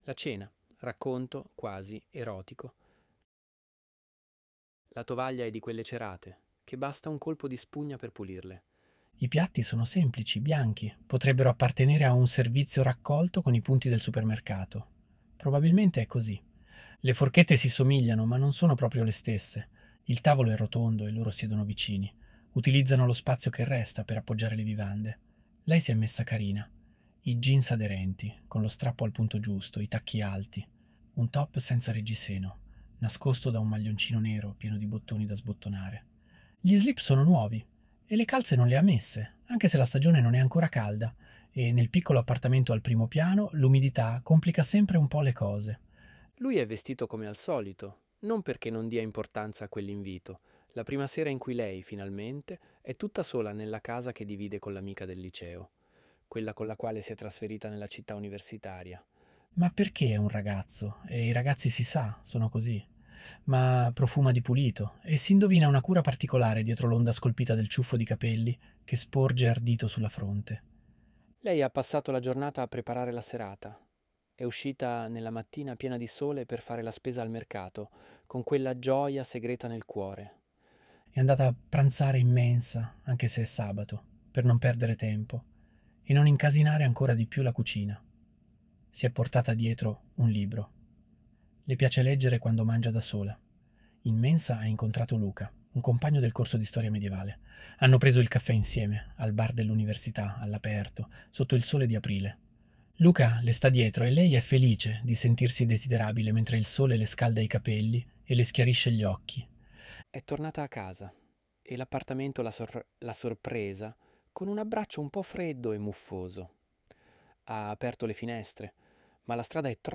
ElevenLabs_La_cena_–_Racconto_quasi_erotico.mp3